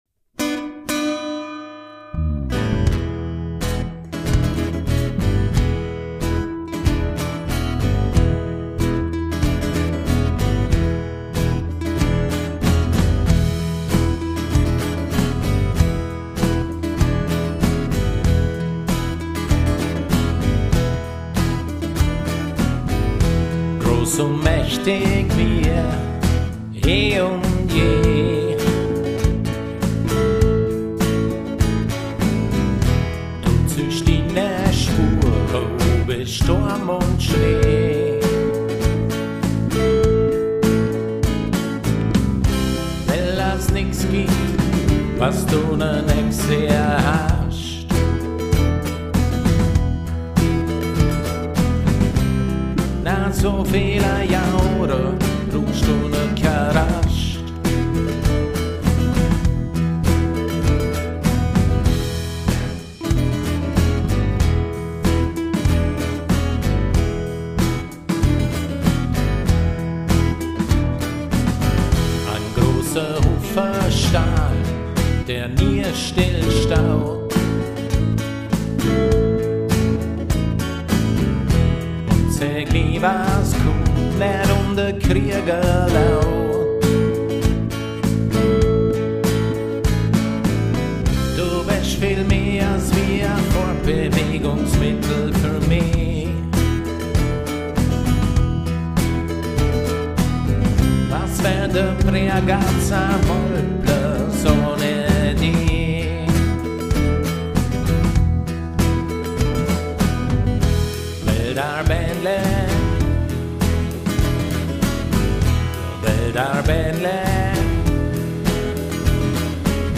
Handgemachte Musik im Bregenzerwälder-Dialekt